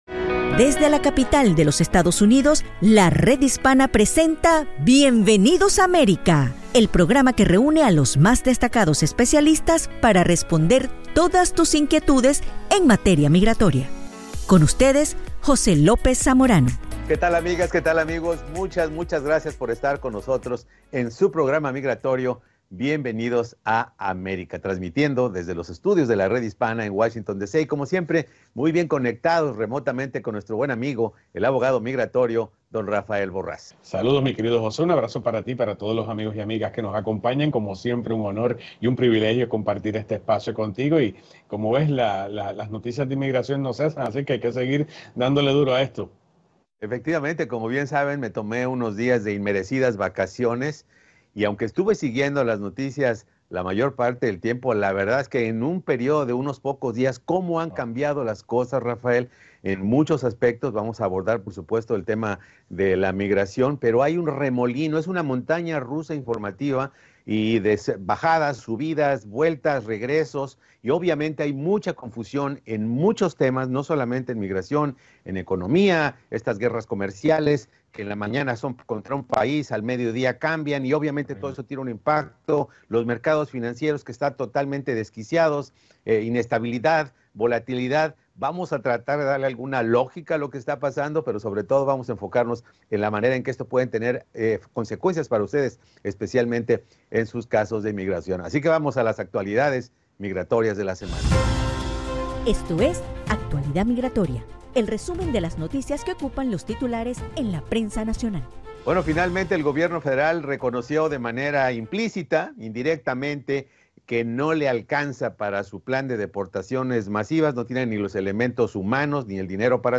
analizan la situación y responden preguntas de la audiencia